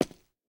footsteps / rails
rails-02.ogg